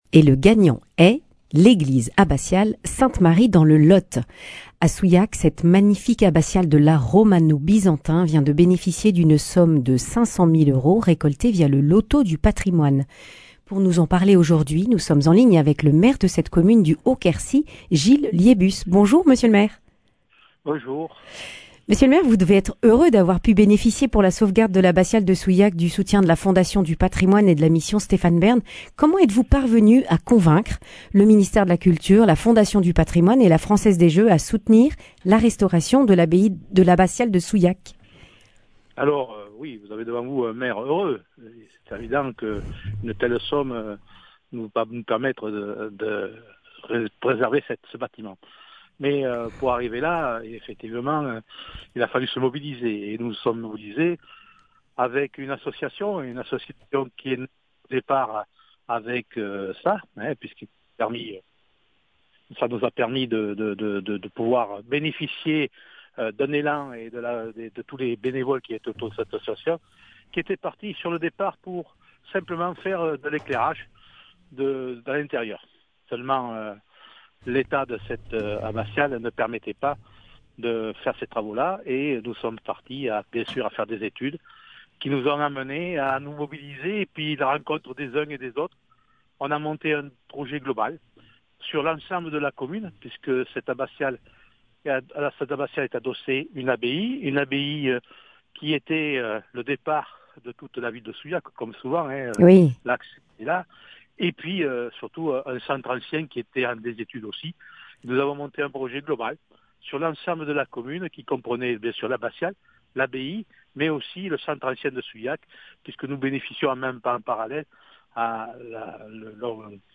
L’ensemble composé de l’abbaye, de l’abbatiale et du bourg a été sélectionné par Stéphane Bern pour recevoir une somme provenant du loto du patrimoine. Récit avec le maire de Souillac, Gilles Liébus.
Le grand entretien